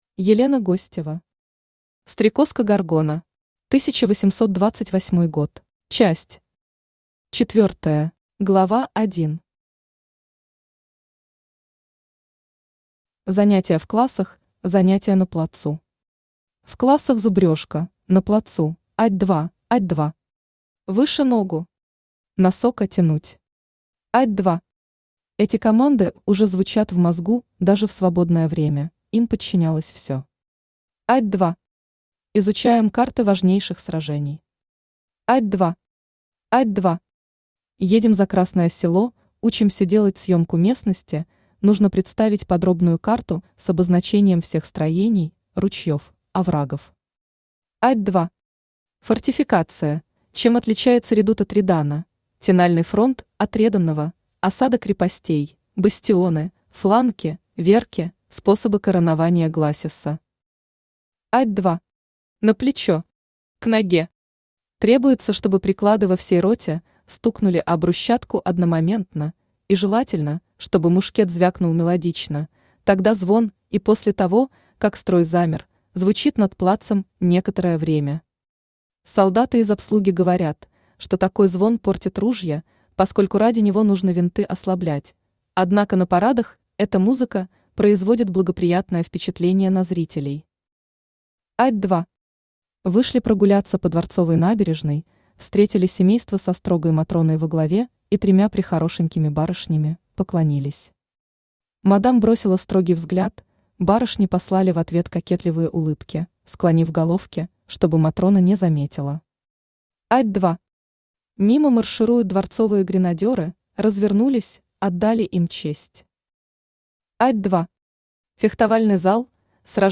Аудиокнига Стрекозка Горгона. 1828 год | Библиотека аудиокниг
Aудиокнига Стрекозка Горгона. 1828 год Автор Елена Гостева Читает аудиокнигу Авточтец ЛитРес.